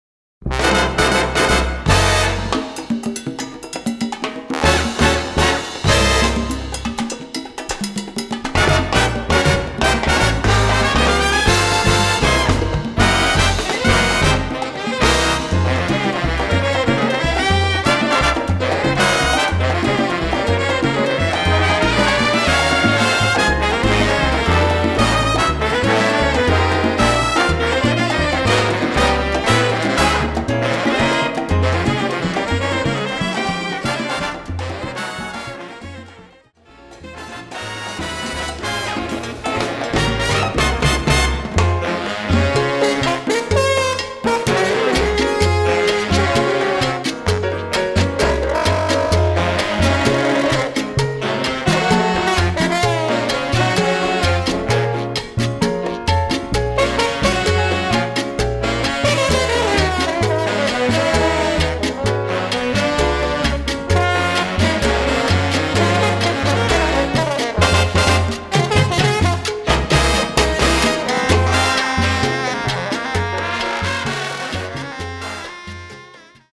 Category: big band
Style: mambo
Solos: trumpet, trombone, alto, bari
Instrumentation: (big band) 4-4-5, rhythm